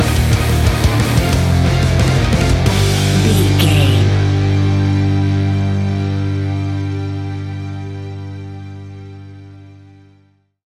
Action Heavy Rock Epic Music Stinger.
Epic / Action
Fast paced
Aeolian/Minor
hard rock
heavy metal
rock instrumentals
Heavy Metal Guitars
Metal Drums
Heavy Bass Guitars